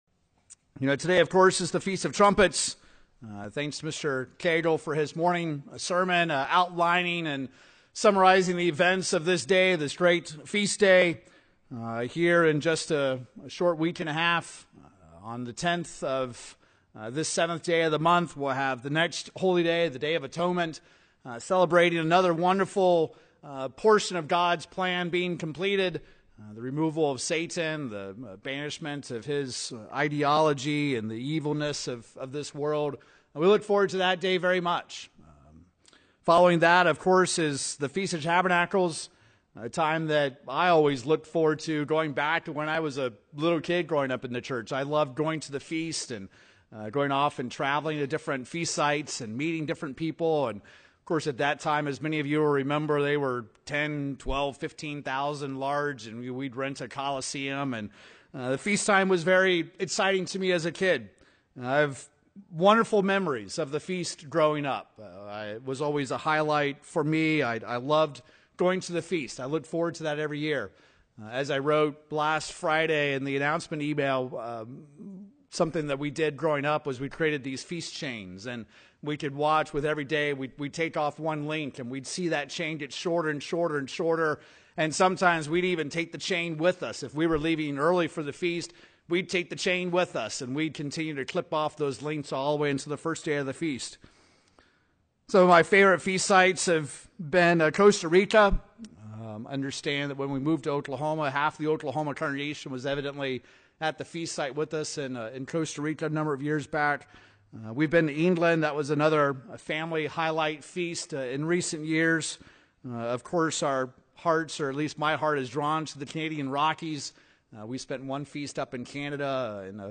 But are we realizing and striving for the underlying reason God wants us to keep these days? When Jesus Christ returns, He will be looking for something specific. In this Feast of Trumpets sermon, let's see from the pages of the Bible what our Christ is looking for and how we can ready for His return.